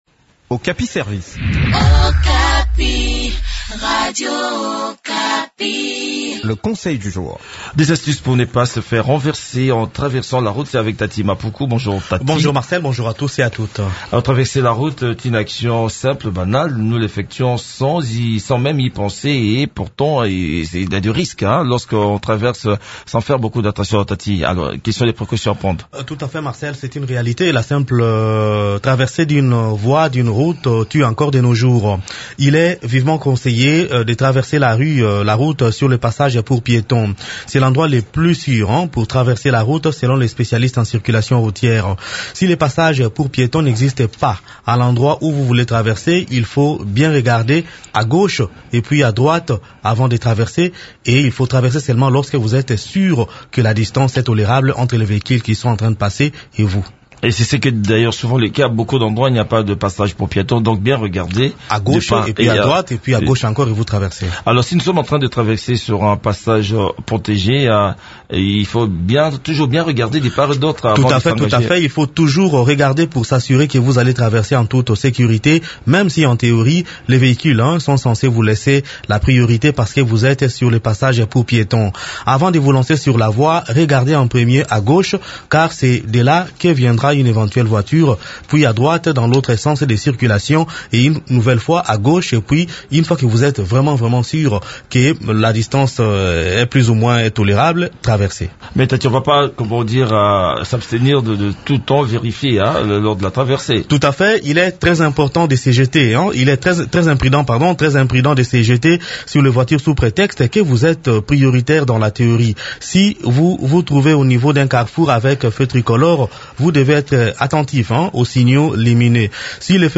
Découvrez quelques astuces qui peuvent vous aider à traverser la route dans cette chronique